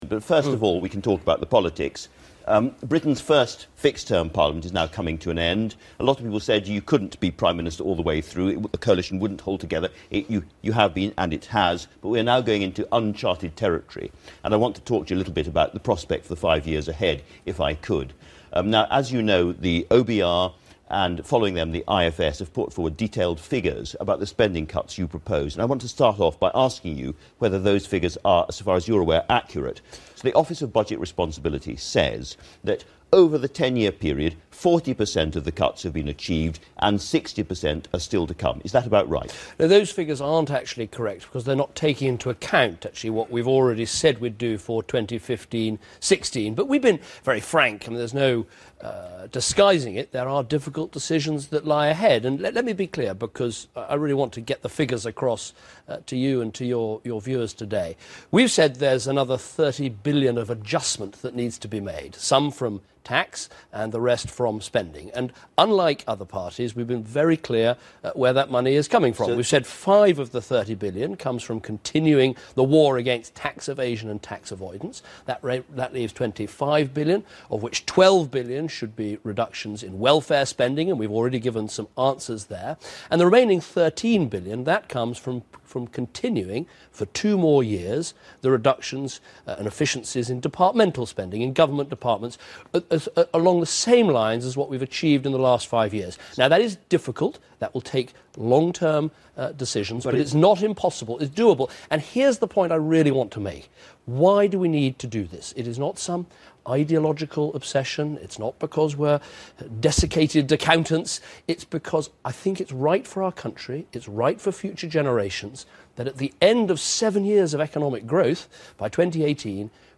David Cameron's interview with Andrew Marr